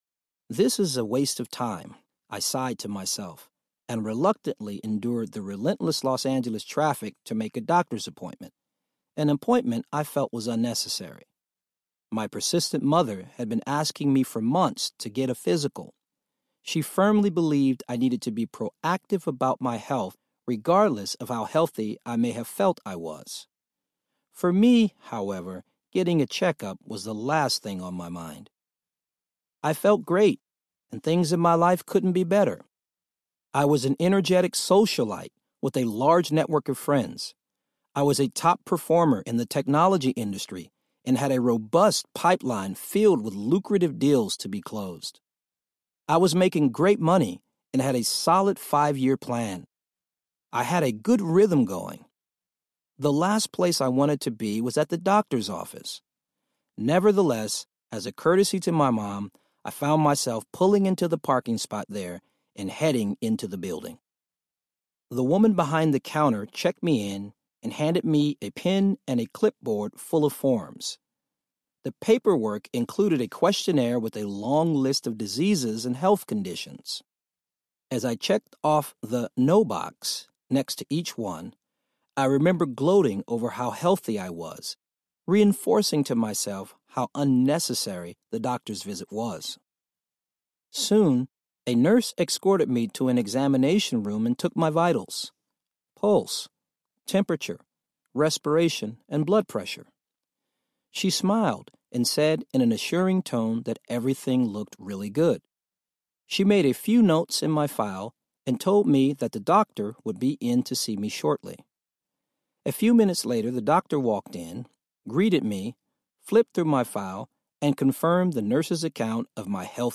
Wholeness Audiobook
Narrator
– Unabridged